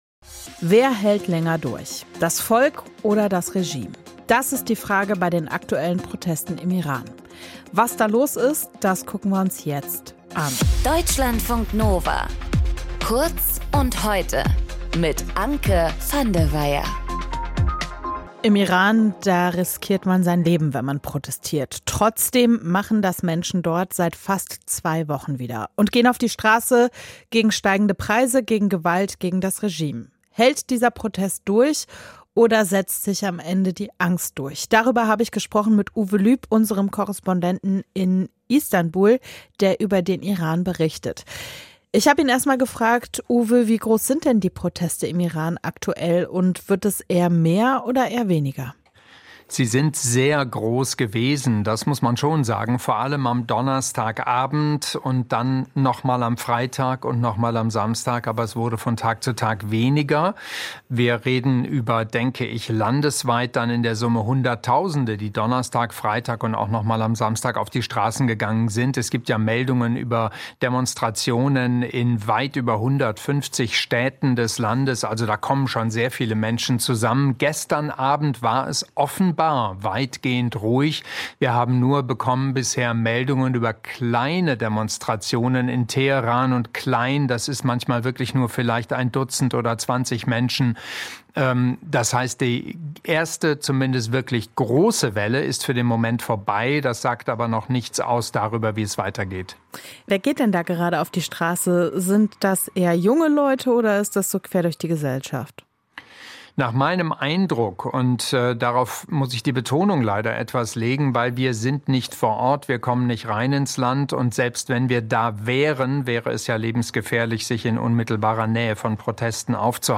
Moderatorin: